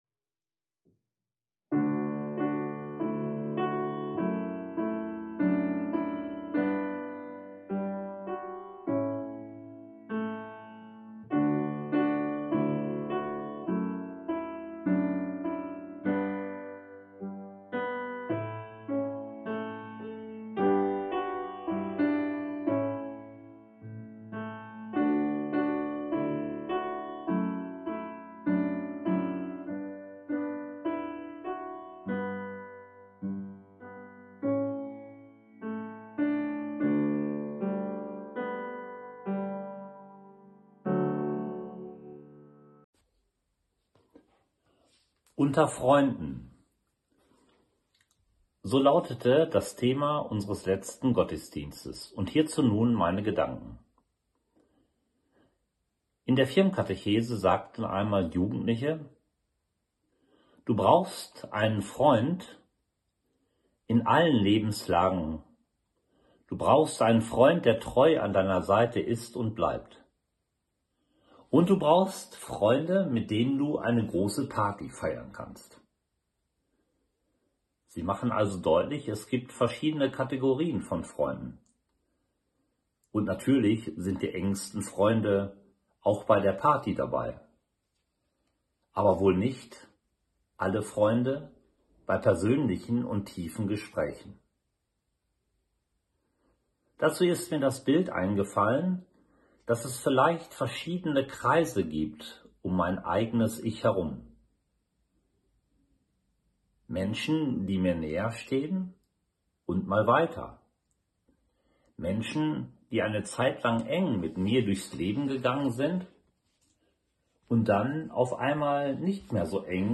„Unter Freunden“, so lautete das Thema unseres letzten Gottesdienstes im September, am Fest der Begegnung. Unter Freunden sein, das ist ein wunderbares, lebensstärkendes Gefühl.